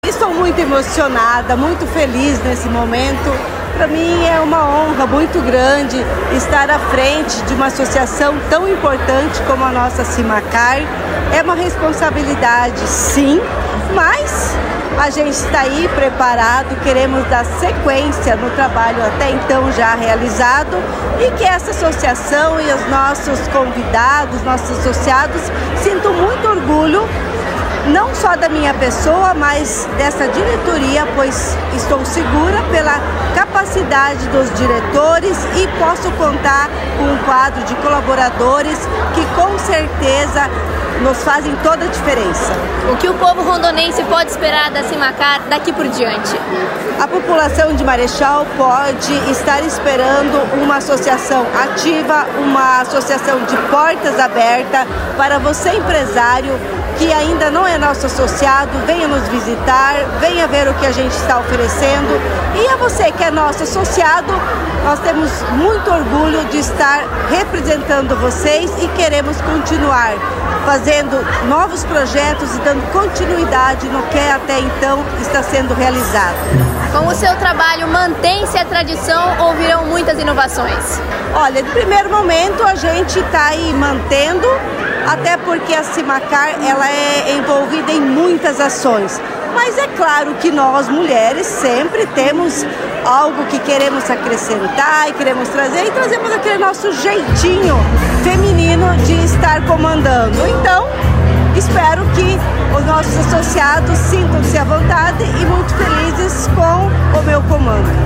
No último sábado foi realizada mais uma cerimônia de posse dos novos diretores e representantes da associação que ajuda o município desde a primeira década de emancipação político administrativa